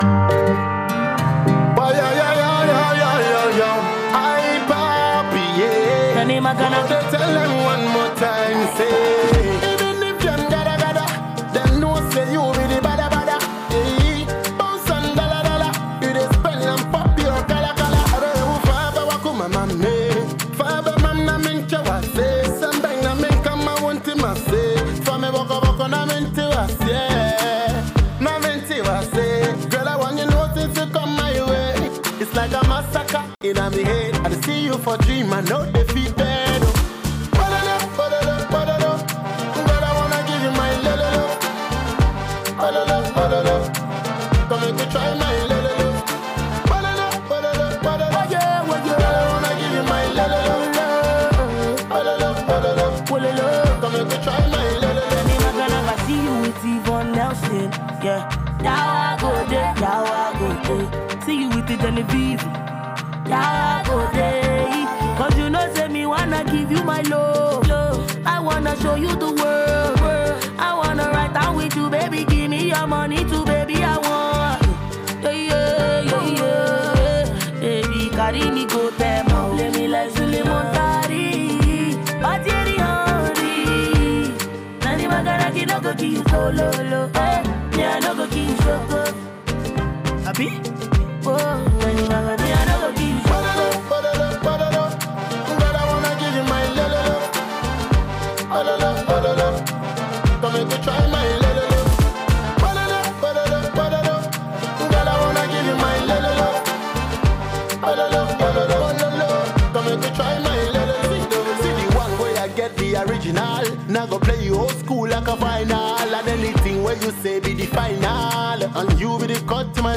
Ghanaian Dance Hall artist
catchy Afro dance-hall tune.